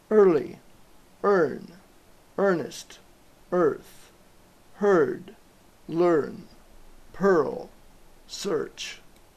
EAR+consonant es pronuncia /ər/